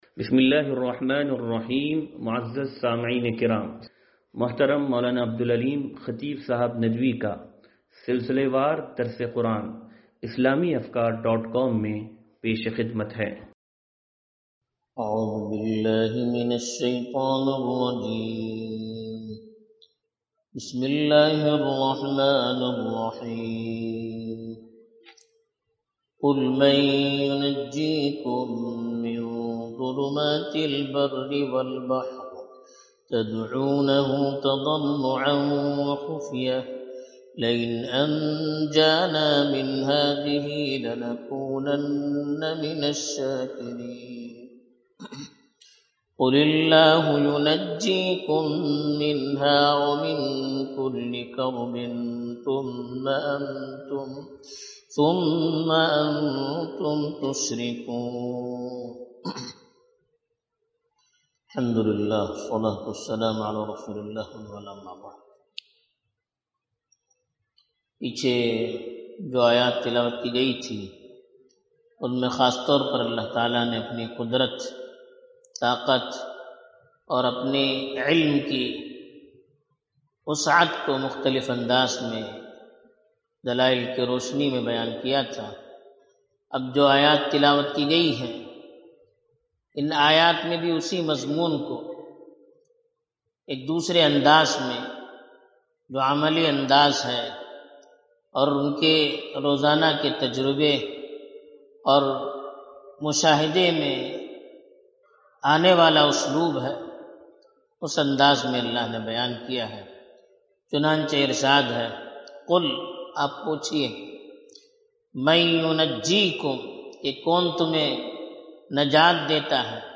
درس قرآن نمبر 0533